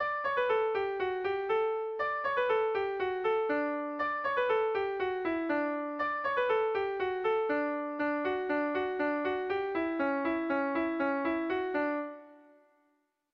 Dantzakoa
Lau puntuko berdina, 8 silabaz
A1A2A3A2